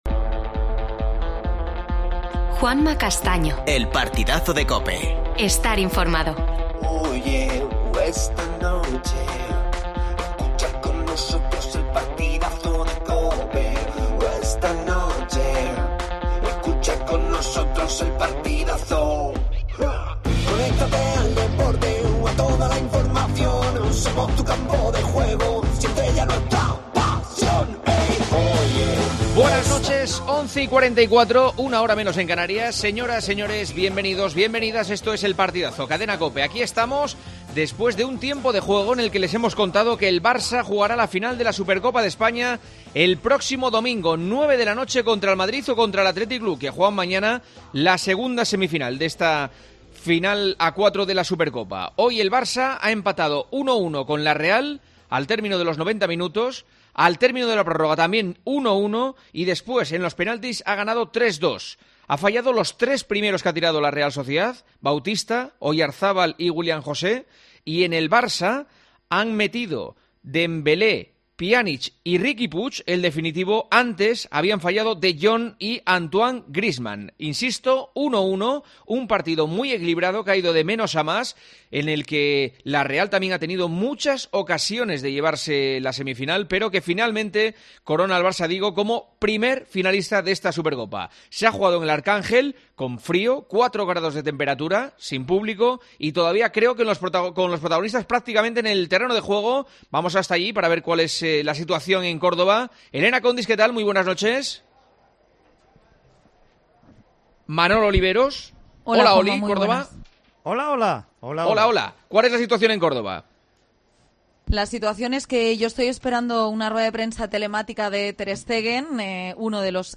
AUDIO: Titulares del día. El Barça derrota a la Real Sociedad en las semifinales de la Supercopa en los penaltis. Escuchamos a Ter Stegen y Mikel Merino.